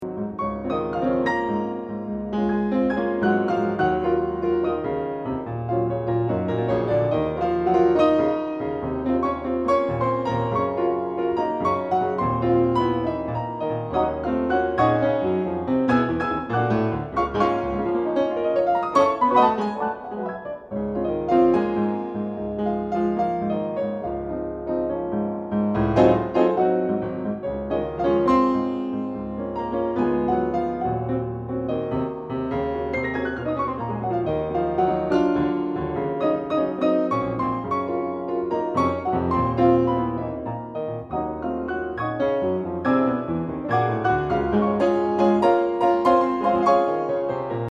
Delightful Jazz Tunes for Two Pianos
The superb engineering also helps.